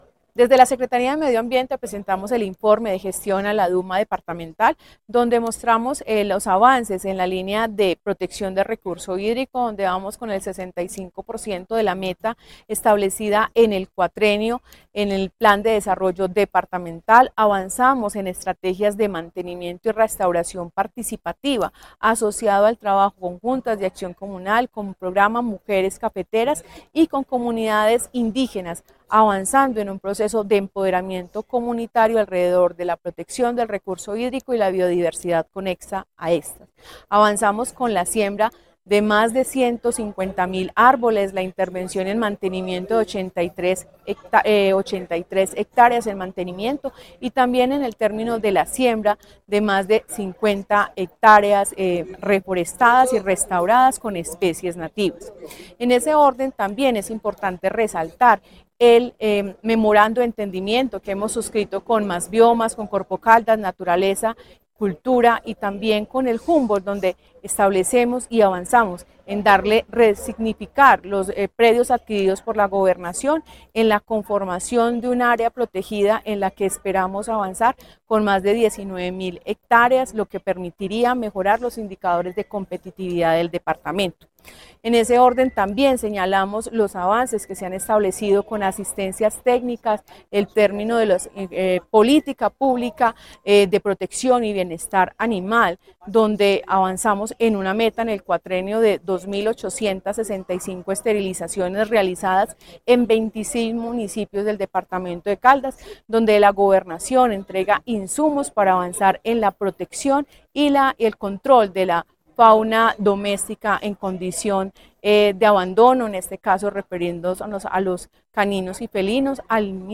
Paola Andrea Loaiza Cruz, secretaria de Medio Ambiente.
Paola-Andrea-Loaiza-Cruz-secretaria-de-Medio-Ambiente.mp3